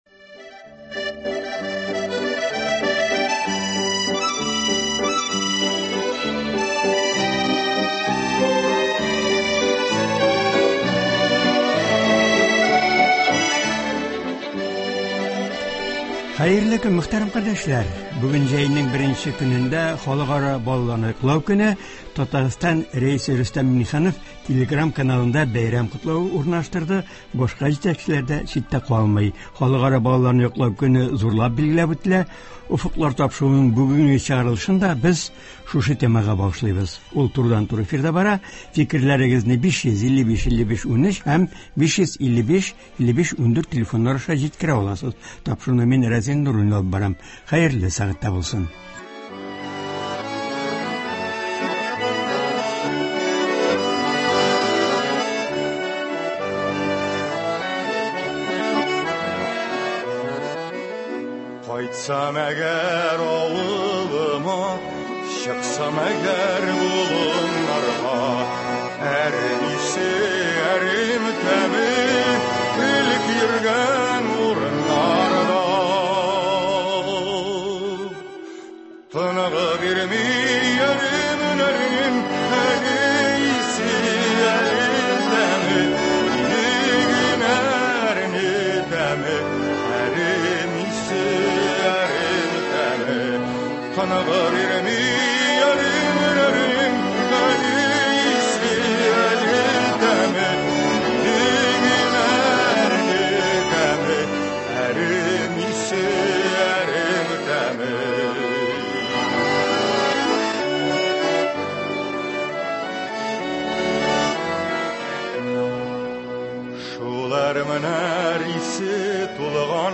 Турыдан-туры элемтә тапшыруында катнашып, ул Татарстанда театр сәнгатенә булган игътибар турында сөйли, җырлар башкара һәм тыңлаучылардан килгән сорауларга җавап бирә.